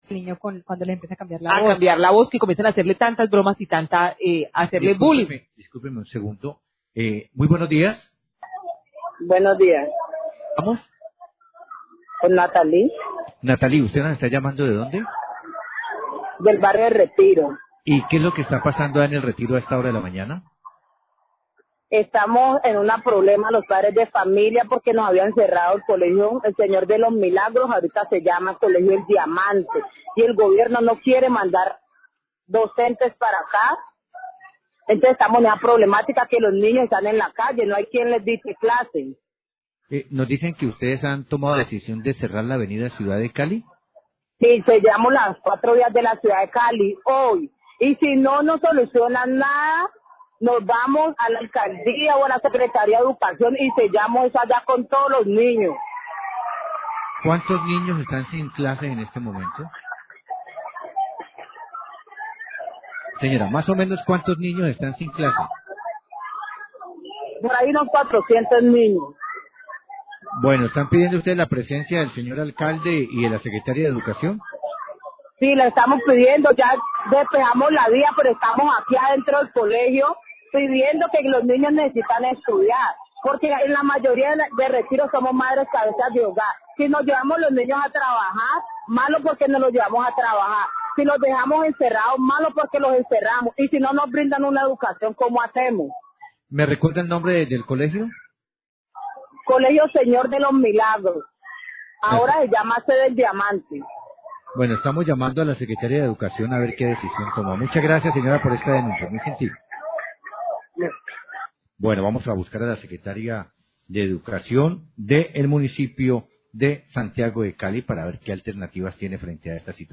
Radio
Habitante del barrio El Retiro dice que se plantaron y cerraron el paso en la avenidad ciudad de Cali para exigir a la Alcaldía que les envíen profesores al colegio del sector, pues son cerca de 400 niños que se encuentran si educación.